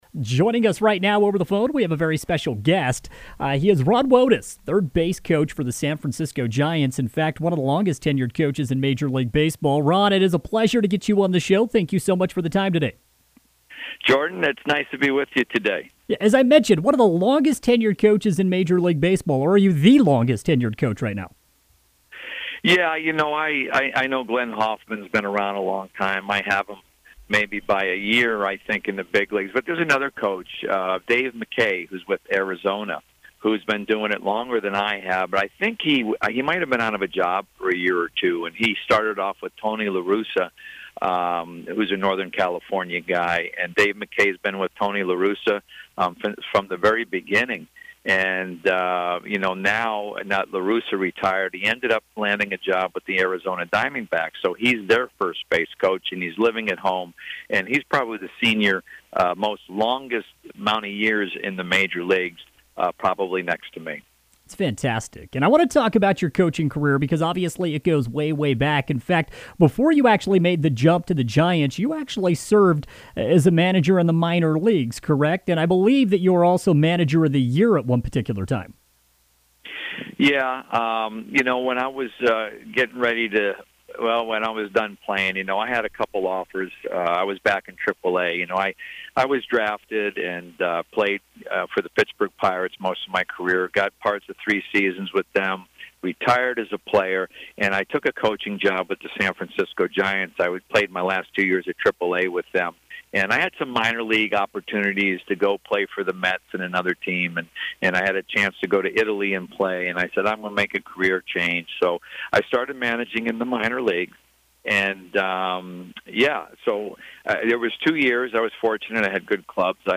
Ron Wotus, current third base coach, three-time World Series Champion; longest tenured coach in Giants franchise history, joins Drive Time Sports Friday afternoon for a conversation about his career, three-world series championships, the world of analytics and more.